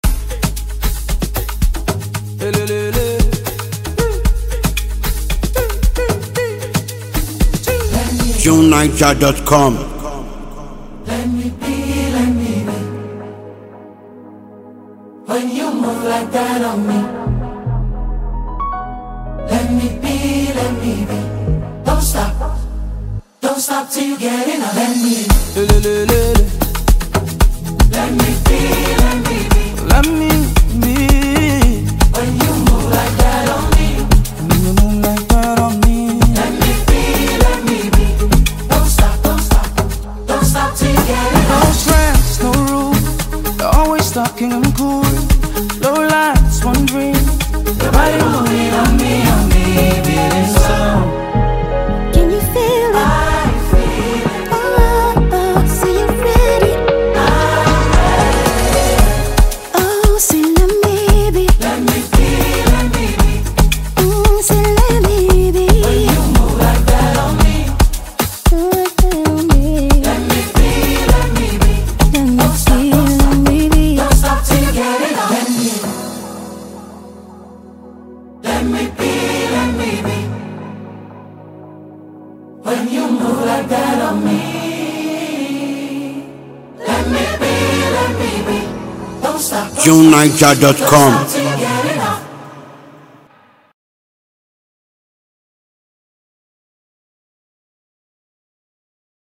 The powerful gospel performance